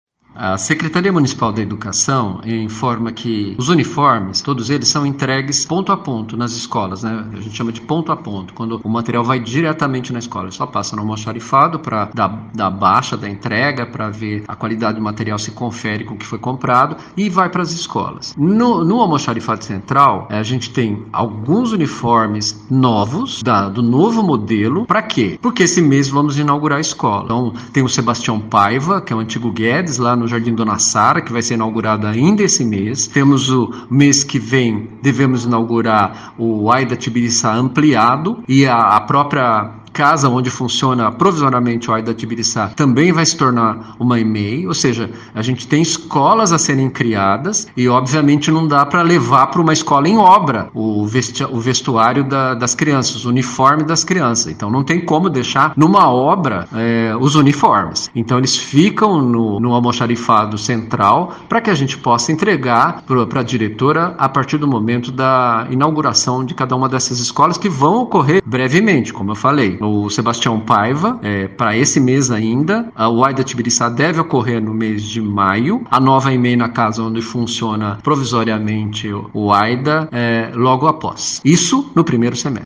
Presidente da Comissão de Justiça, vereadora Estela Almagro, falou sobre a vistoria realizada.
Já Chiara Ranieri, presidente da Comissão de Educação, lembrou que a Câmara já apurava a compra dos uniformes deste ano, ao custo de 18 MILHÕES DE REAIS e, as investigações serão ampliadas.